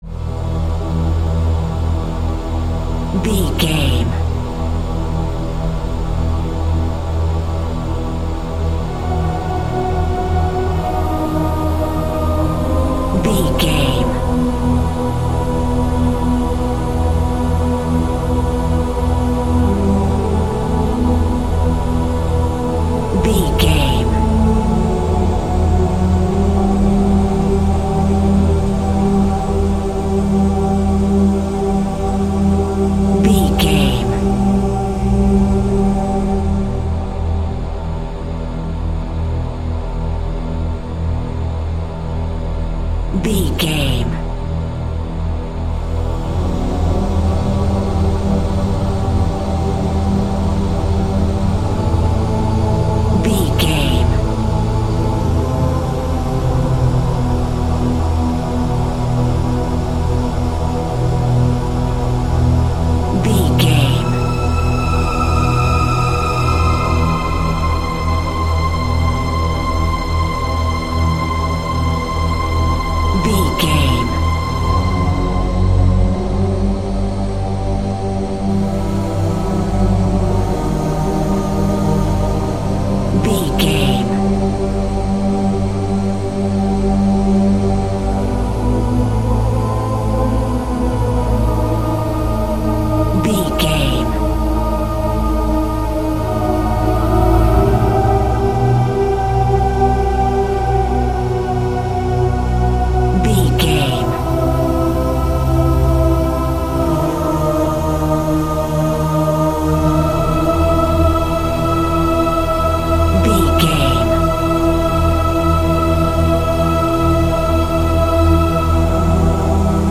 Aeolian/Minor
scary
ominous
dark
suspense
eerie
synthesiser
horror
keyboards
ambience
pads
eletronic